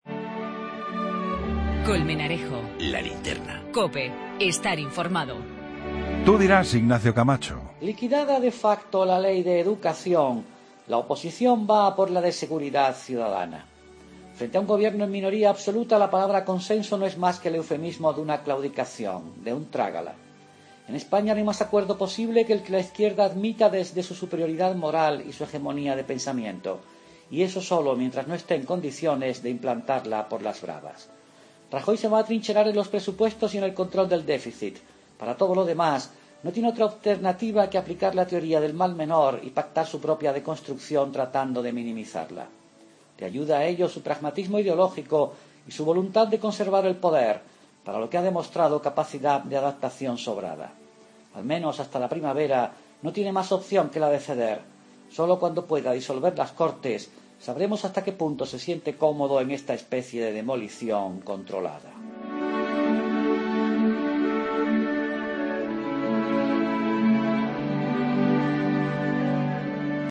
AUDIO: El comentario de Ignacio Camacho en 'La Linterna'.